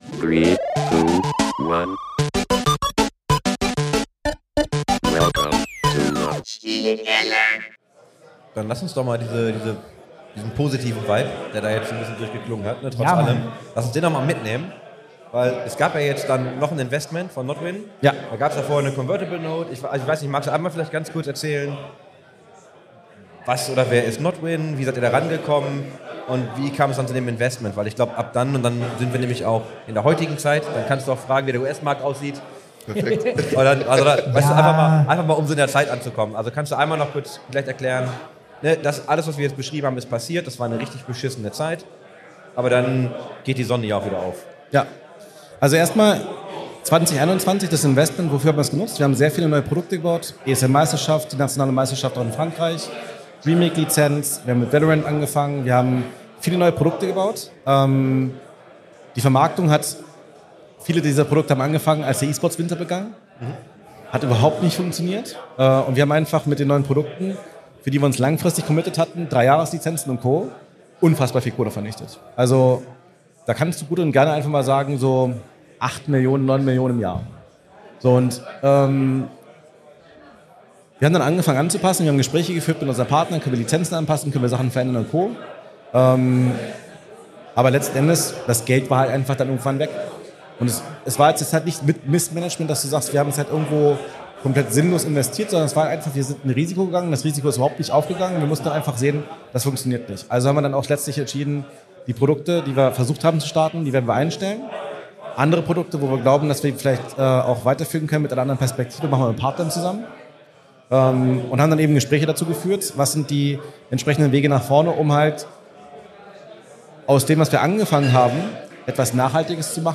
Diese fand am 31.05. im Berliner XPERION statt.
Da die Live-Session insgesamt über 1,5 Std lang war, wurde sie in zwei Parts aufgeteilt. Hier in Part 2 geht es um den aktuellen Stand bei Freaks 4U Gaming, das Investment in das Unternehmen durch die Nodwin Gruppe und gemeinsame Zukunftspläne.